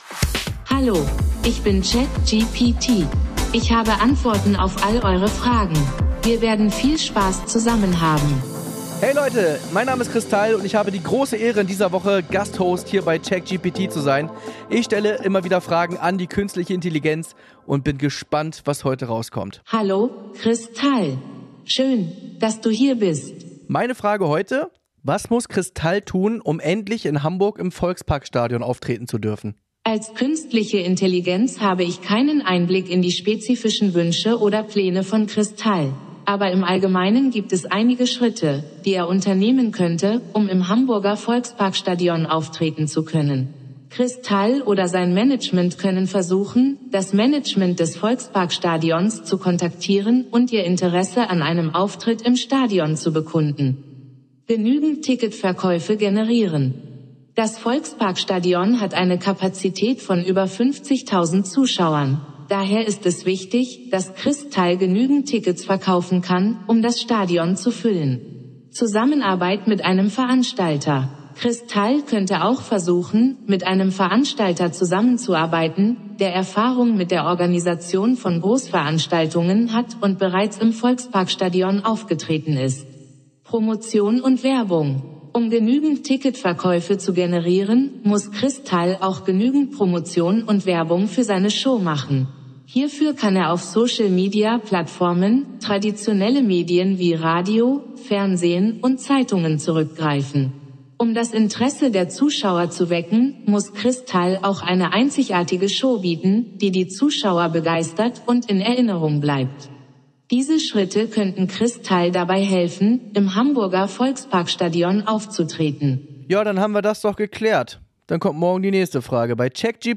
Verlängerung für unseren Gast-Host Chris Tall: Deutschlands